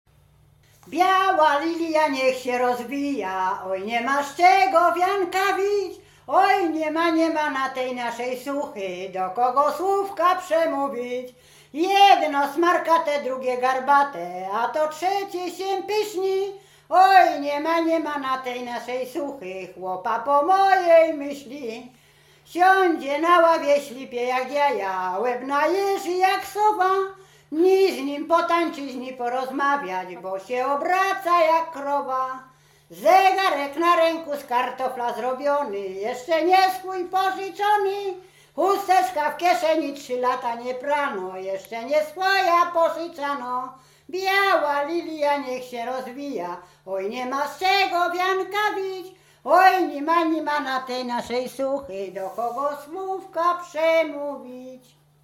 Łęczyckie
województwo łódzkie, powiat poddębicki, gmina Wartkowice, wieś Sucha Dolna
miłosne liryczne żartobliwe